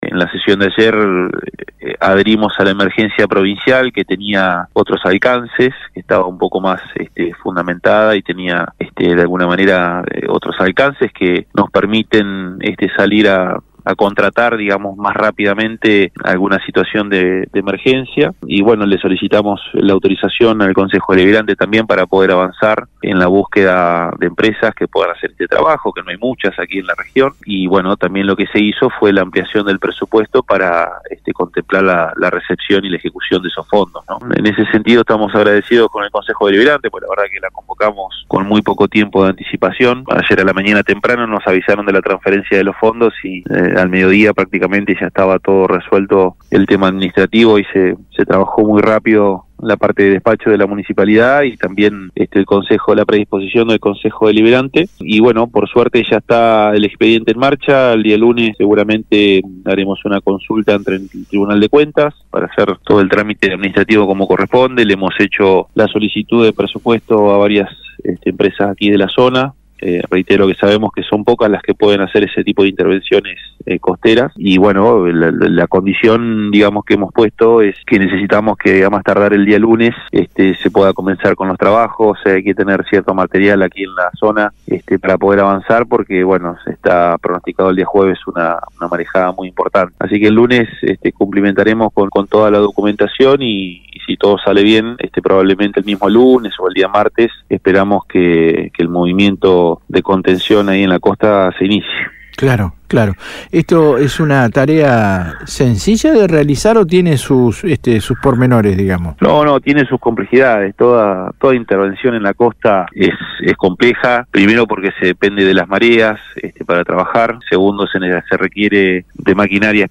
En dialogo con nuestra emisora en el programa Piedra Libre el jefe comunal detalló com0 ingresaron los fondos provistos por el gobierno chubutense, para poder adquirir los insumos y contratar las obras necesarias.